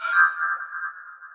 Fantasy ui buttons sounds » Fantasy ui Button 3
描述：Fantasy_ui_Button ui chimes crystal chime bell fairy sparkle jingle magic ethereal tinkle spell airy sparkly
标签： fairy chime jingle ethereal ui sparkly Fantasy ting tinkle sparkle airy bell Button crystal magic spell chimes
声道立体声